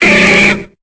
Cri de Machopeur dans Pokémon Épée et Bouclier.